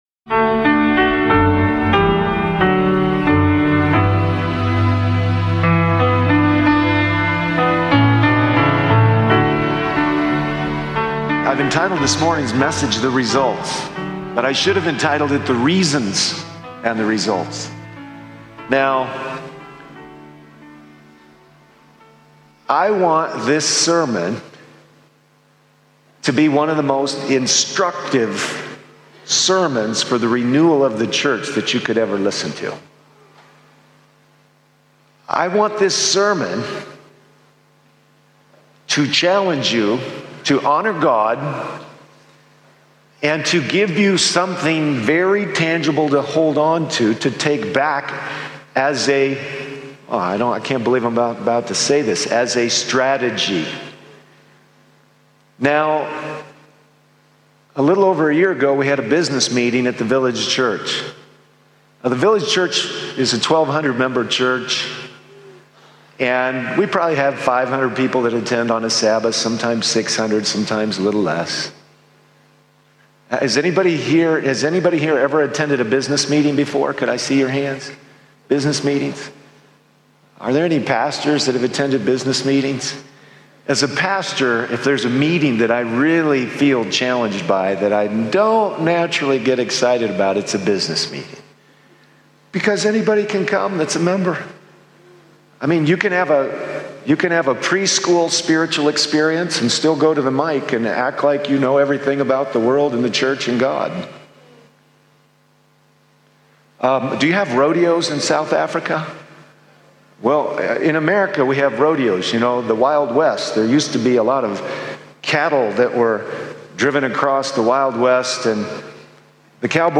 Is your church truly following the voice of the Good Shepherd—or just following trends? This sermon challenges believers to return to Christ-centered leadership, Spirit-led planning, authentic discipleship, and holistic mission, showing how surrender, stewardship, and spiritual unity build a church that thrives in both power and purpose.